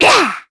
Nia-Vox_Attack1_kr.wav